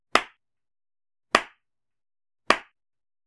slowclap.ogg